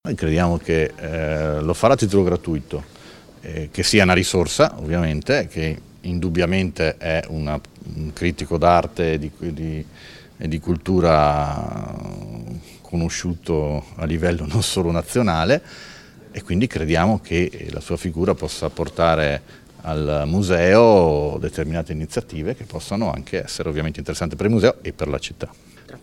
int_Fugatti_-_Mart.mp3